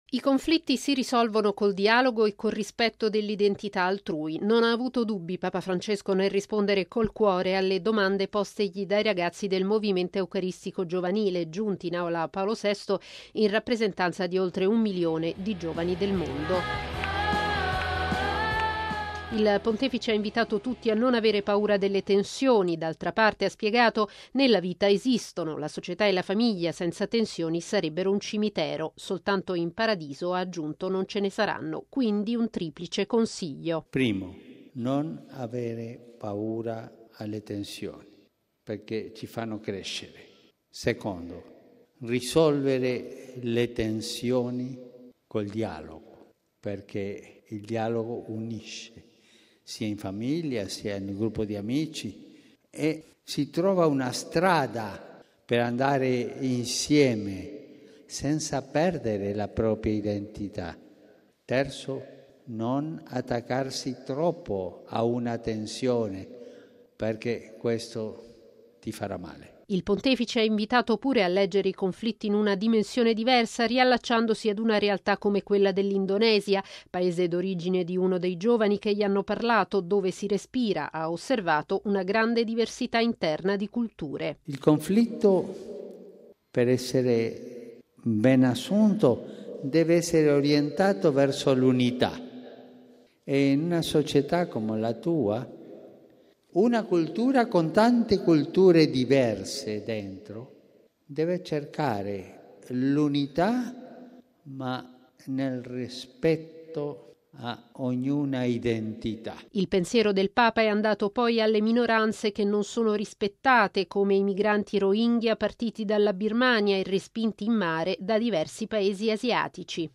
Tensioni e conflitti si superano col dialogo e nel rispetto dell'identità degli altri. Lo ha detto Papa Francesco, parlando a braccio, nell'Aula Paolo VI, ai ragazzi del Movimento Eucaristico Giovanile (Meg) promosso dai Gesuiti, a Roma per l’incontro mondiale in occasione del centenario di fondazione.